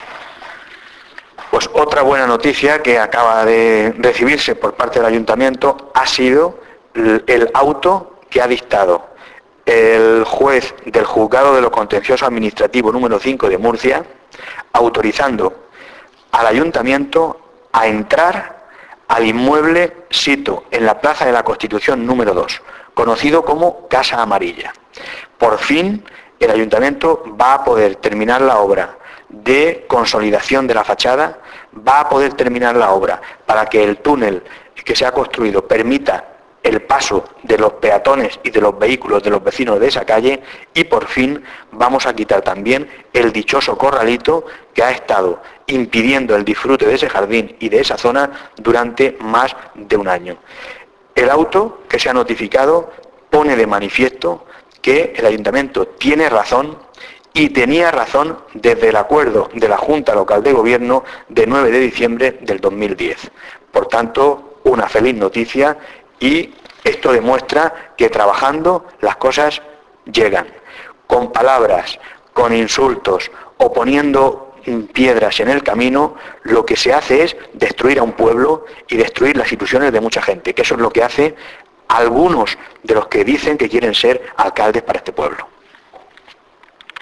Descargar: El alcalde habla de esta autorización subir